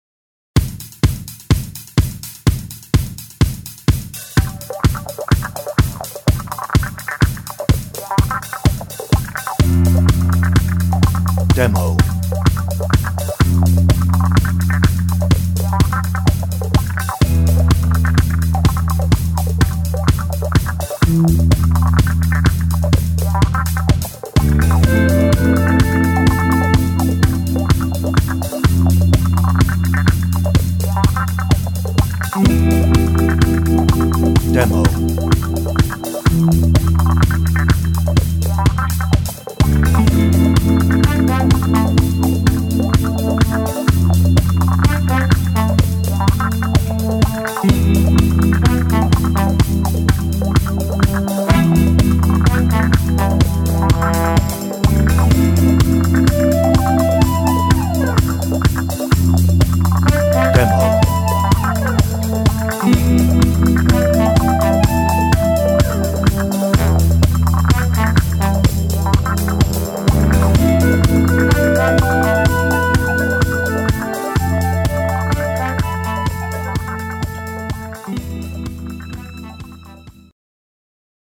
no ref vocal
Instrumental